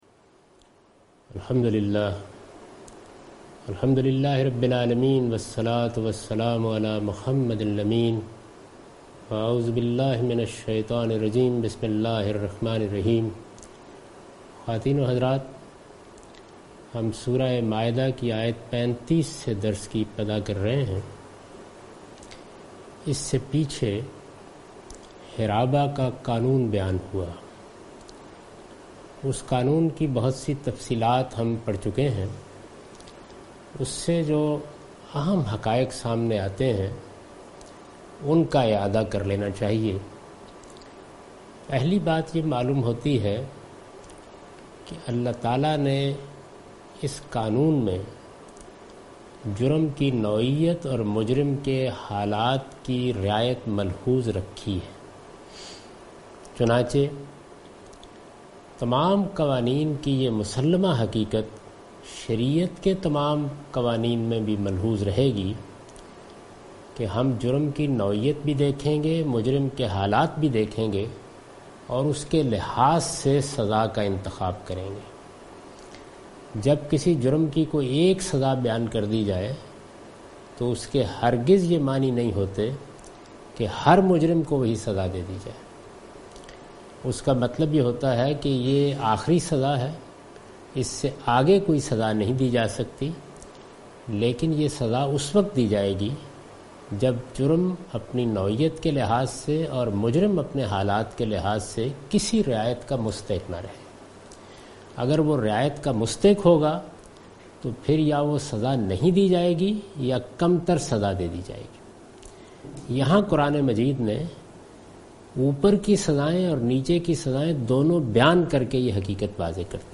Albayan Quran Class – Lecture 14 (Verse 34 to 40 Surah Maidah)